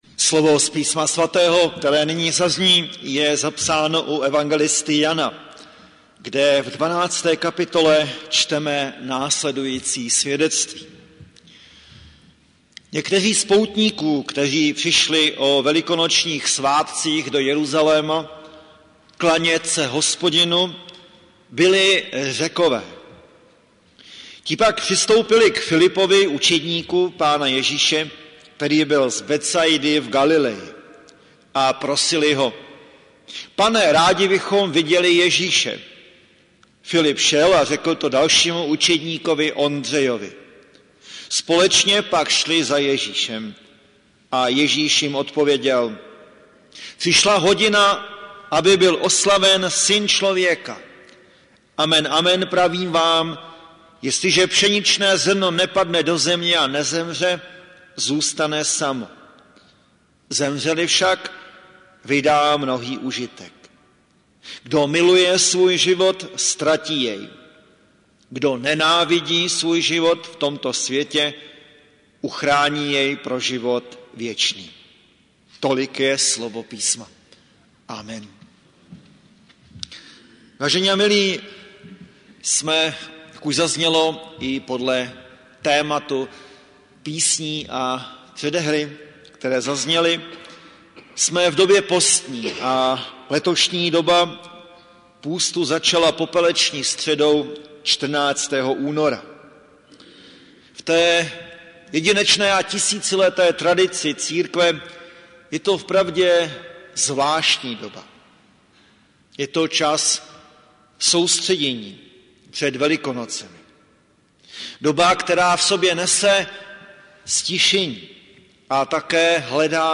Hudební nešpory - Barokní postní hudba pro sbor a varhany • Farní sbor ČCE Plzeň - západní sbor
Barokní postní hudba pro sbor a varhany
varhanní pozitiv z 18. století